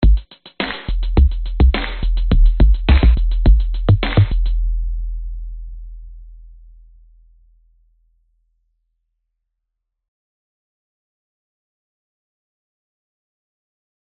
昏昏欲睡的节奏
Tag: 节拍 循环